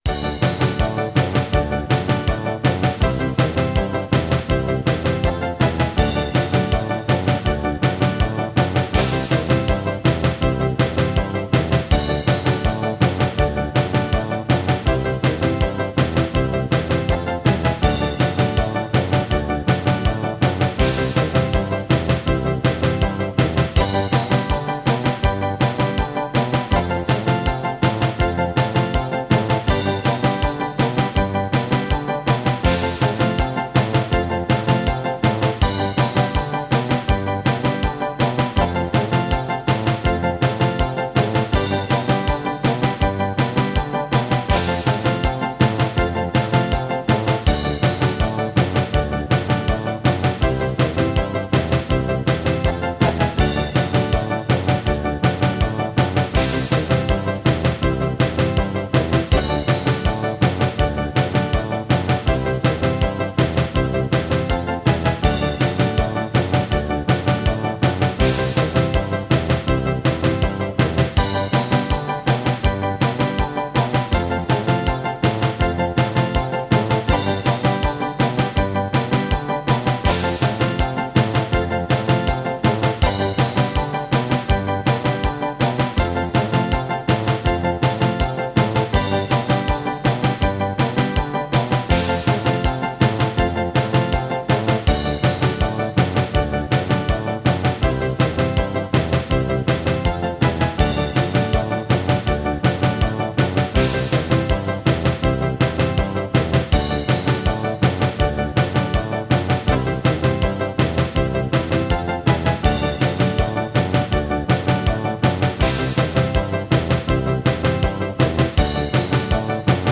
Tempo: 80 bpm / Datum: 17.01.2017